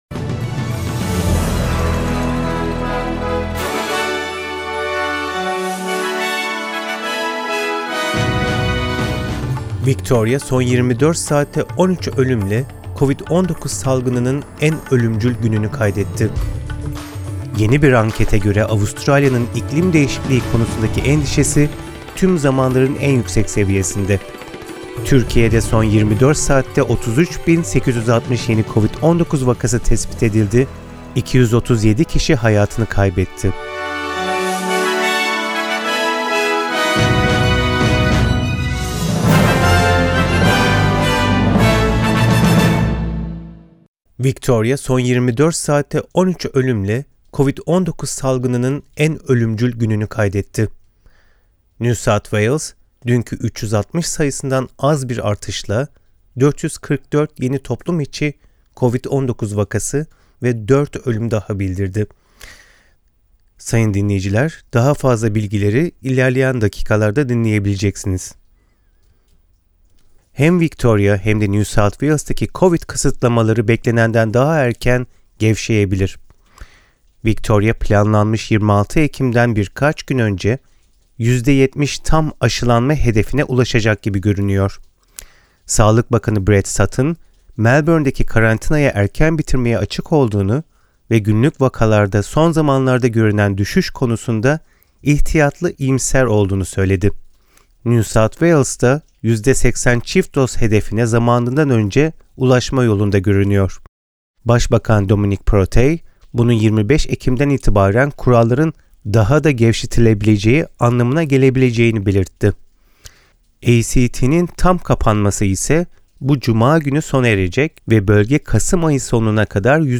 SBS Türkçe Haberler 13 Ekim